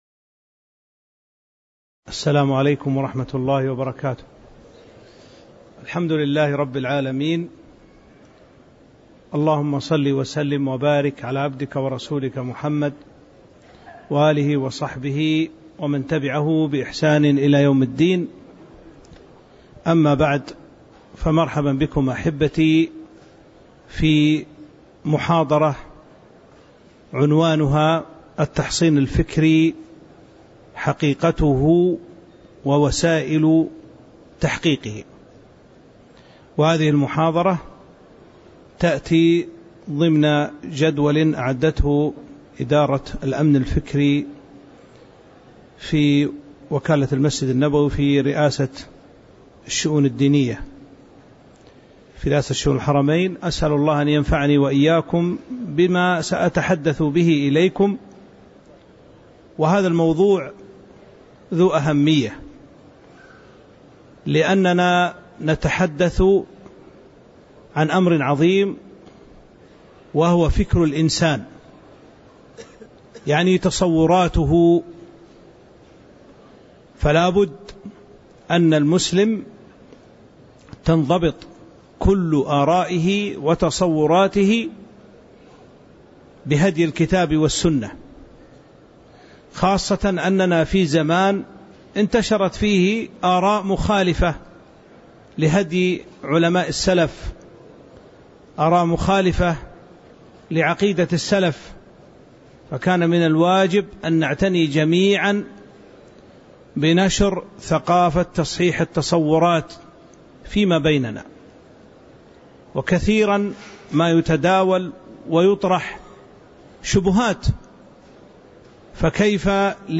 تاريخ النشر ١٠ ذو القعدة ١٤٤٥ هـ المكان: المسجد النبوي الشيخ